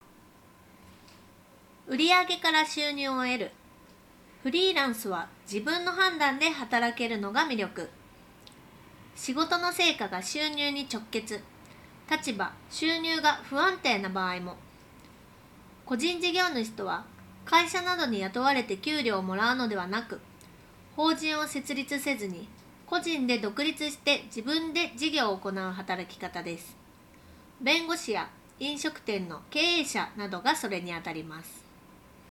ノイキャン効果は高く、周囲のノイズを効果的に取り除き、装着者の声のみをクリアに拾い上げることができていた。
さすがに専用のハイエンドマイクと比べると録音品質は劣るものの、普通に通話するには十分に優れた性能となっている。CCの内蔵マイクよりも収録品質は圧倒的に向上していると感じられた。
▼SOUNDPEATS UUの内蔵マイクで拾った音声単体
UUの録音音声を聴くと、CCのマイクよりも周囲の環境ノイズ(空調音や屋外の音)を効果的に除去し、発言内容を明瞭に拾い上げることができていることが分かる。
soundpeats-uu-review.wav